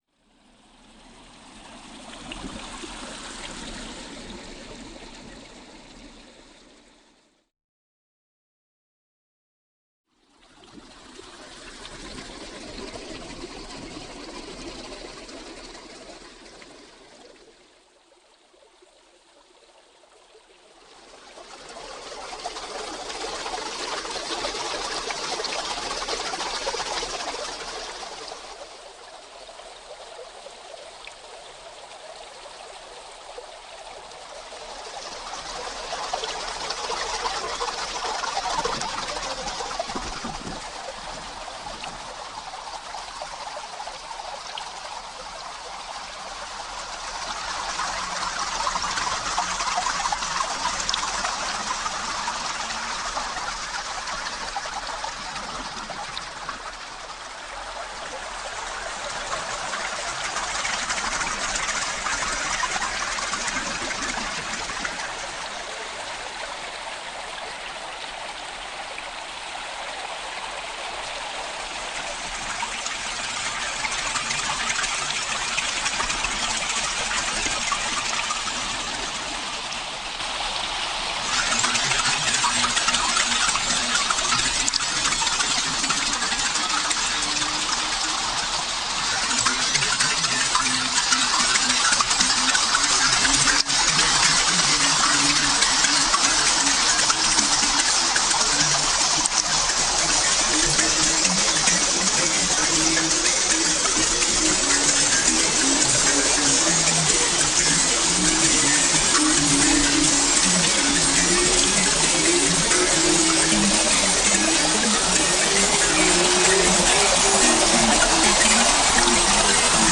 • Instrumentation: experimental electronica